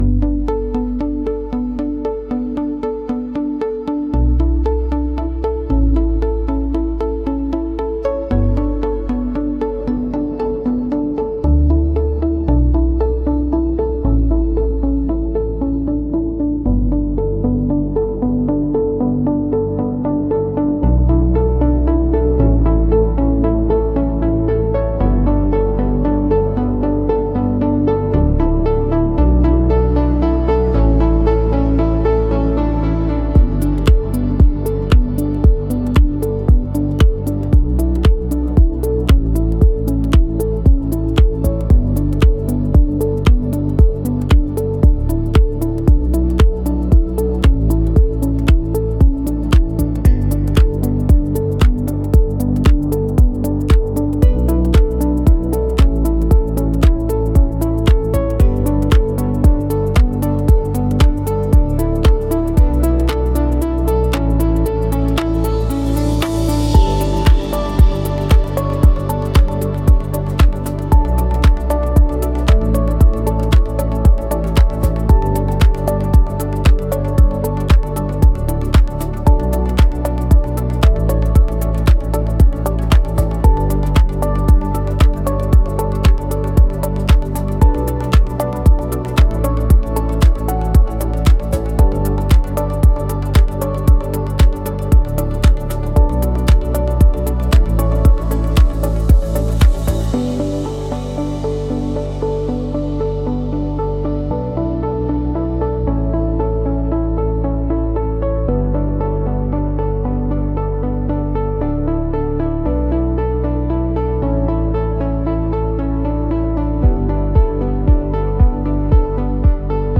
• Атмосферные лупы
• Динамическая фоновая музыка